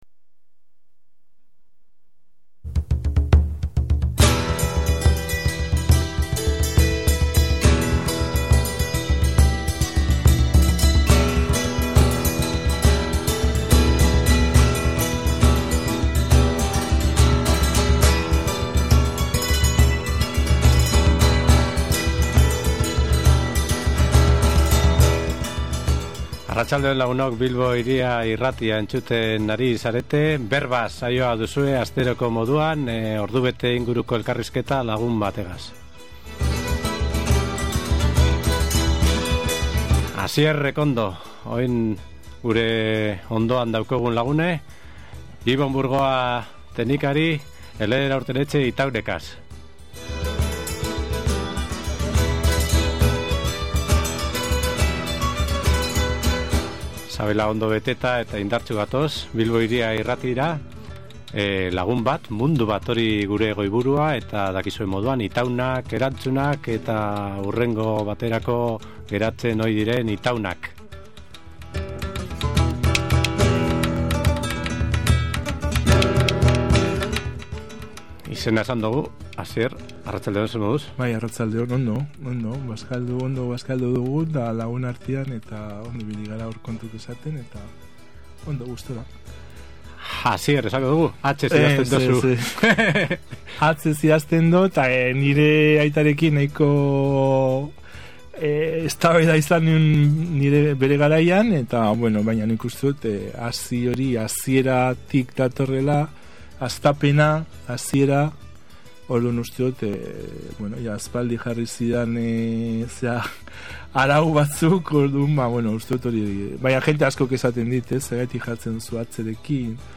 Elkarrizketan bere bizitzari begiratzeko modua, Bermeoko zoroetxean lanean aspaldi emandako hilabeteetako oraitzapenak, berak idazten duen bloga, bere musika kutuna, eta Roberto Bolaño idazlea agertu dira, besteak beste.